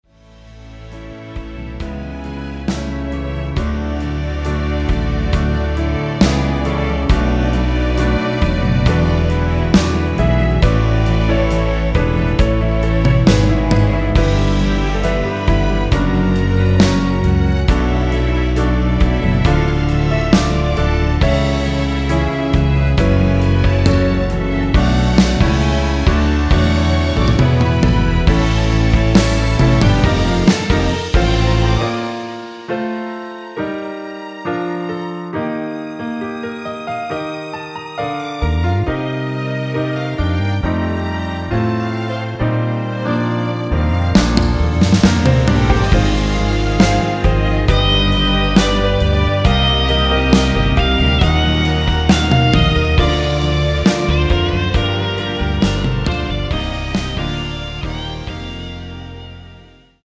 1절후 후렴으로 진행되게 편곡된 MR 입니다.
원키에서(-2)내린 (1절+후렴)으로 편곡된 MR입니다.(미리듣기및 가사 참조)
앞부분30초, 뒷부분30초씩 편집해서 올려 드리고 있습니다.